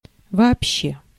Ääntäminen
IPA: /vɐ.ɐˈpɕːe/